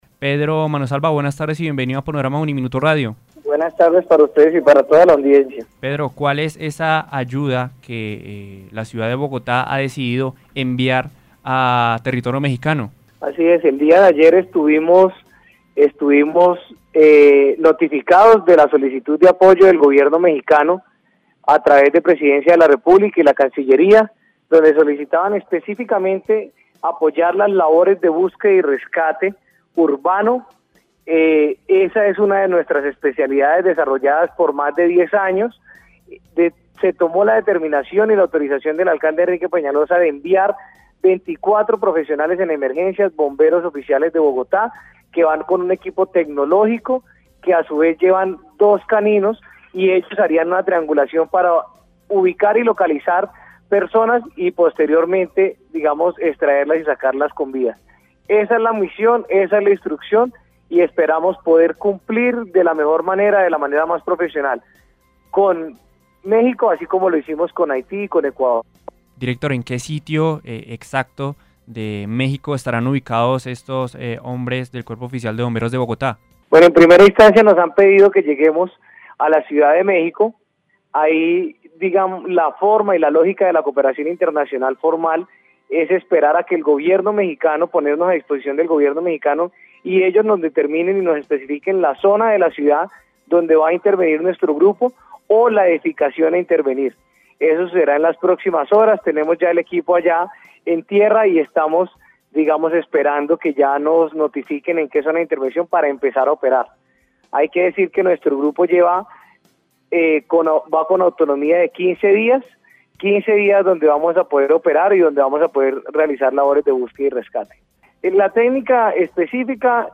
En dialogo con Panorama de Uniminuto Radio estuvo el director del cuerpo oficial de bomberos de Bogotá, Pedro Manosalva , hablando sobre el equipo de 24 rescatistas del grupo especial de búsqueda y rescate que fueron enviados a territorio mexicano para ayudar en las labores de extracción de las víctimas que se encuentran atrapadas bajo los escombros.